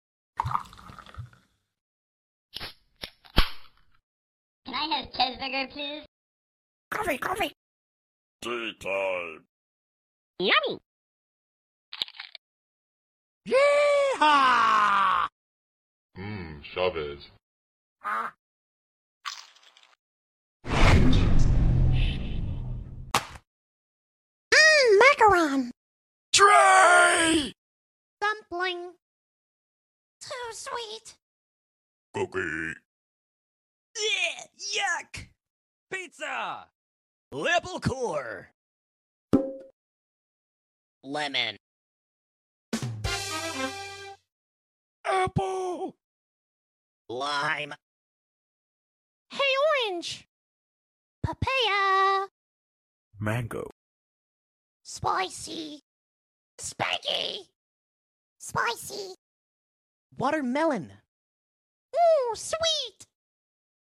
all blocktales food/drink sounds . sound effects free download